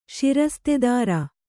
♪ ṣirastedāra